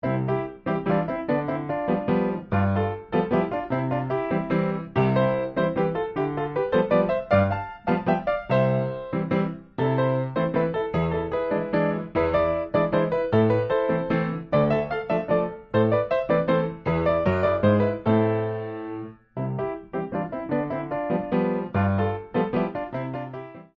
Valzer venezuelani del XIX sec.
Pianoforte
Registrazione audiophile realizzata nei mesi di agosto e settembre 2005 con microfoni e pre-amplificatore a valvole, campionamento a 96 kHz.